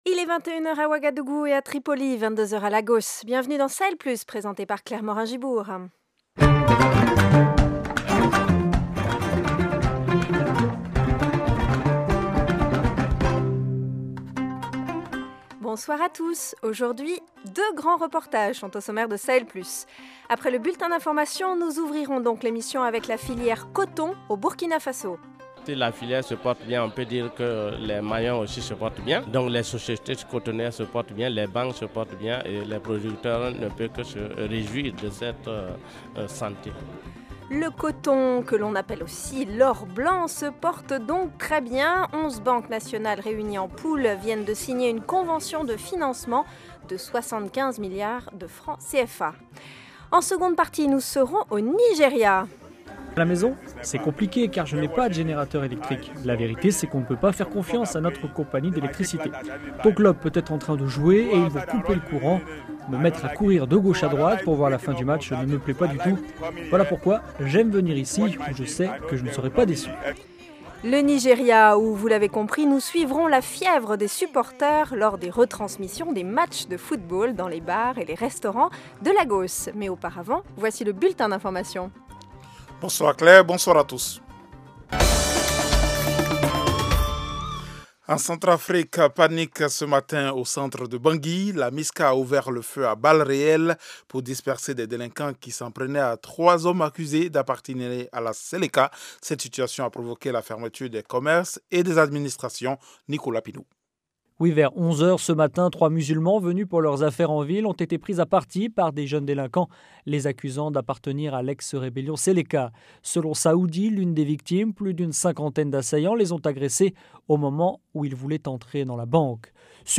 Au programme : deux grands reportages au sommaire de cette émission. La filière du coton, « l’or blanc » se porte bien au Burkina Faso.